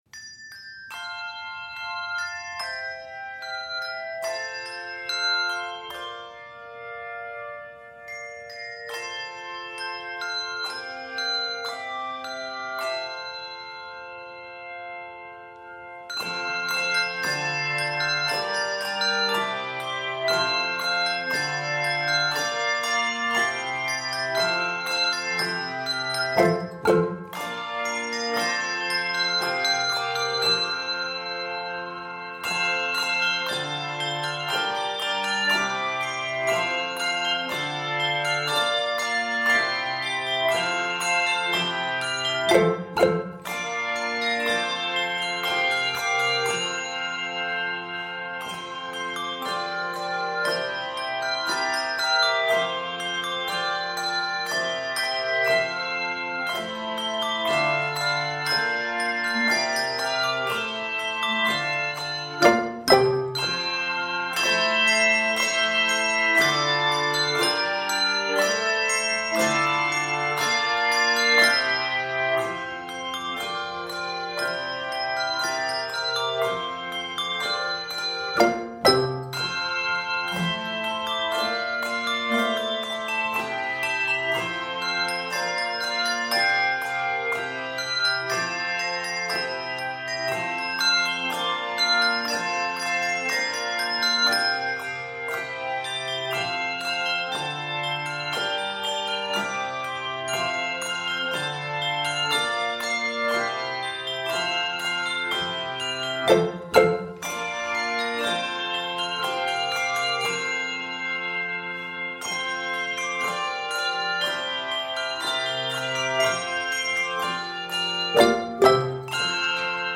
Key of F Major. 77 measures.